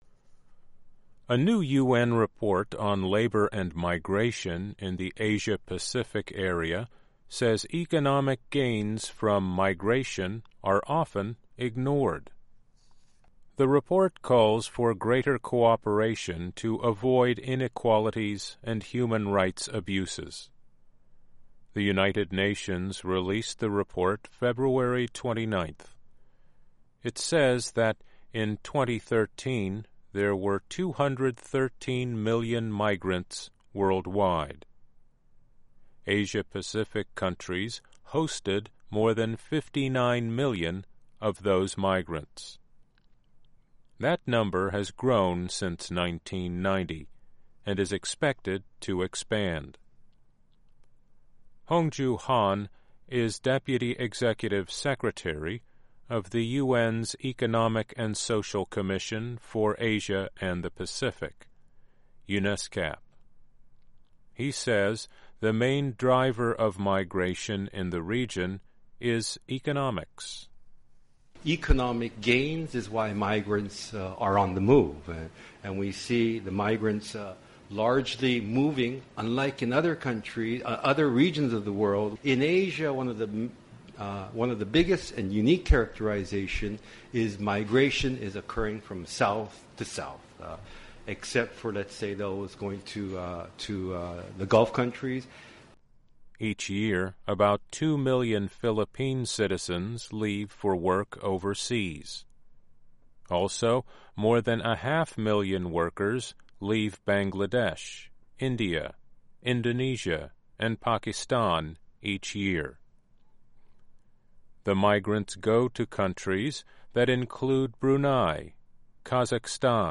Report: Protecting Migrant Workers Helps Host Countries